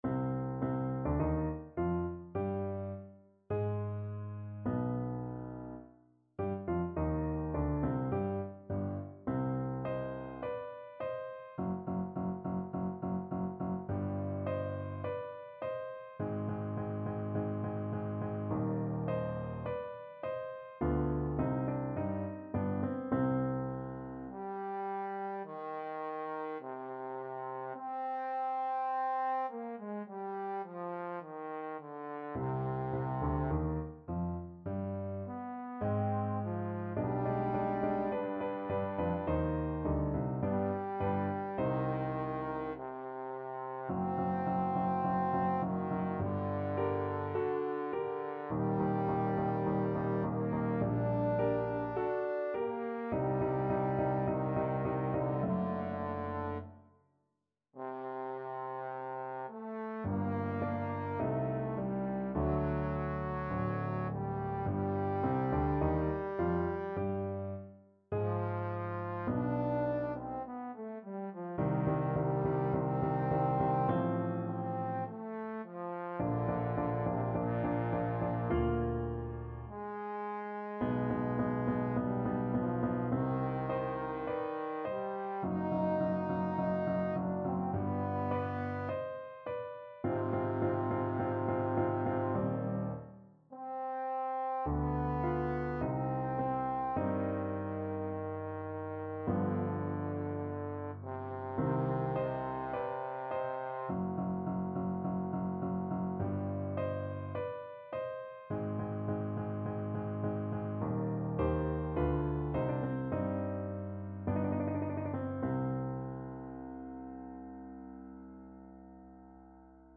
Classical Handel, George Frideric Waft Her, Angels, Through the Skies from Jeptha Trombone version
Trombone
C minor (Sounding Pitch) (View more C minor Music for Trombone )
~ = 52 Recit: Andante
4/4 (View more 4/4 Music)
Classical (View more Classical Trombone Music)
handel_waft_her_angels_TBNE.mp3